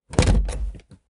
DoorOpen.ogg